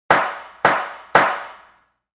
Three Gavel Strikes Sound Effect
Description: Three gavel strikes sound effect.
Genres: Sound Effects
Three-gavel-strikes-sound-effect.mp3